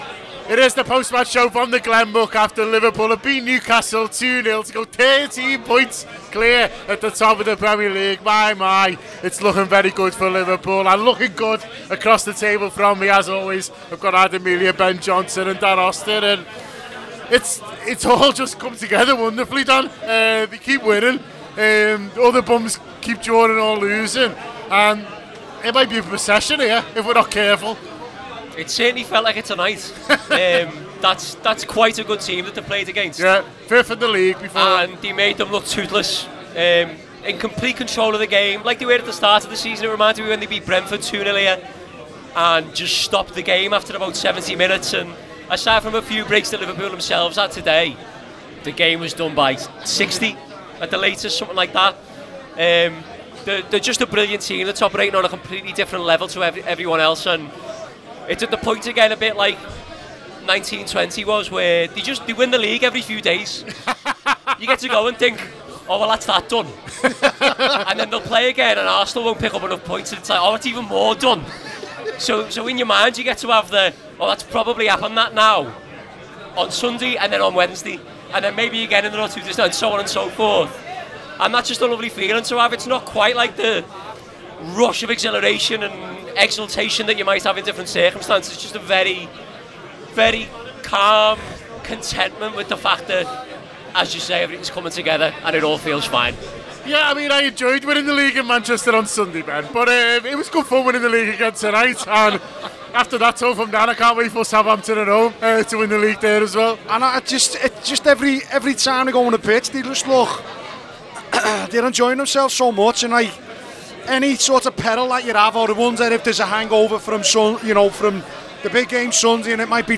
Below is a clip from the show – subscribe to The Anfield Wrap for more reaction to Liverpool 2 Newcastle United 0…